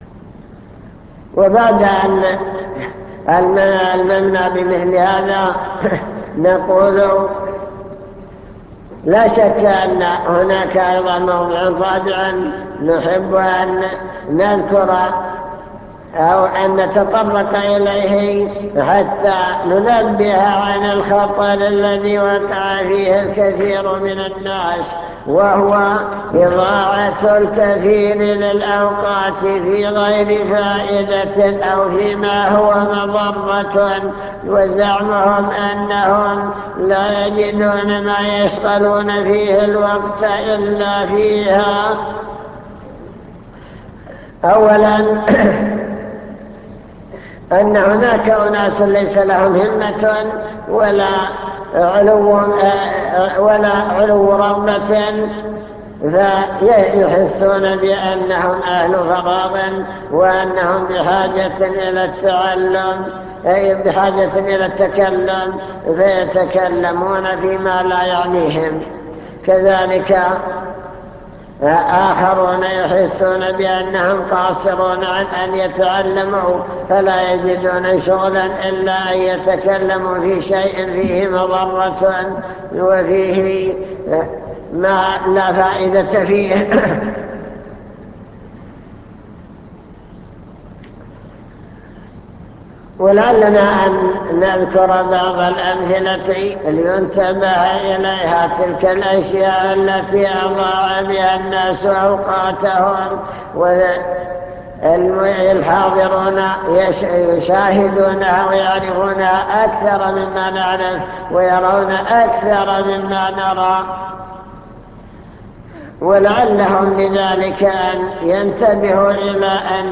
المكتبة الصوتية  تسجيلات - محاضرات ودروس  محاضرة بعنوان الشباب والفراغ التحذير من إضاعة الوقت